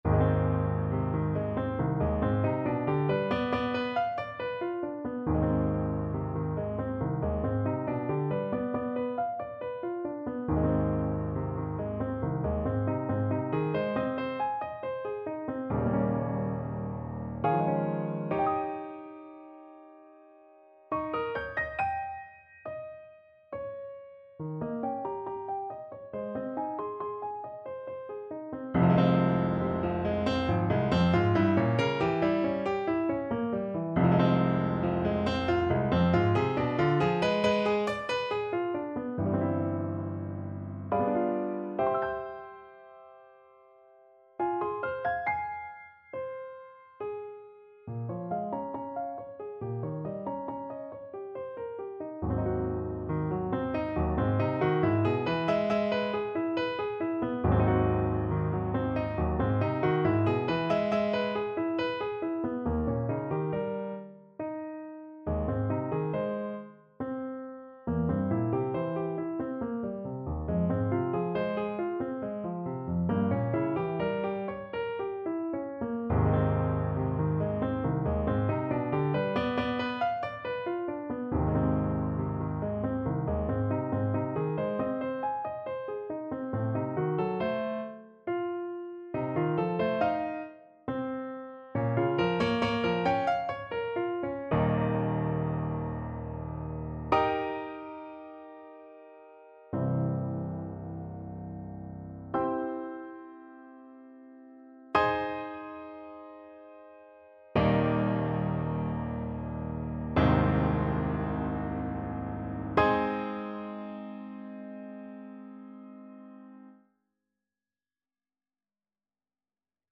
Play (or use space bar on your keyboard) Pause Music Playalong - Piano Accompaniment Playalong Band Accompaniment not yet available reset tempo print settings full screen
Ab major (Sounding Pitch) (View more Ab major Music for Voice )
~ = 69 Large, soutenu
3/4 (View more 3/4 Music)
Classical (View more Classical Voice Music)